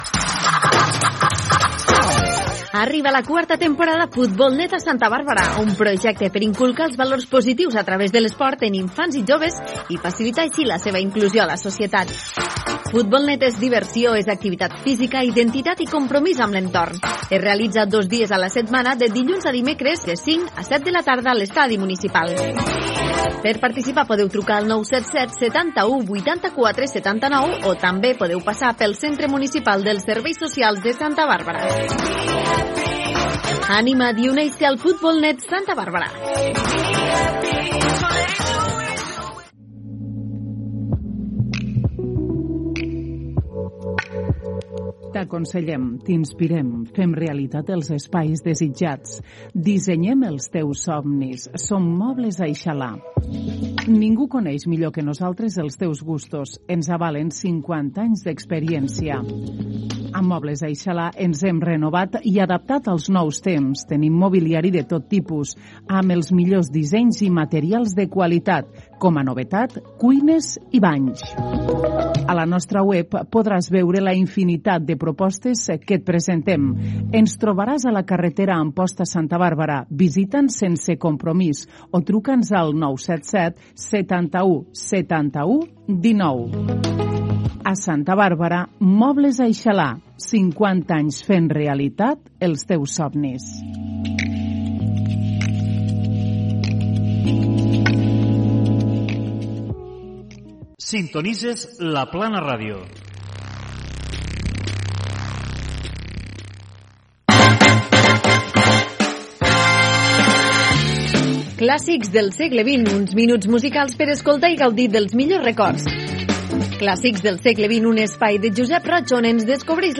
Publicitat, indicatiu de la ràdio, careta del programa, presentació inicial i tema musical
Musical
FM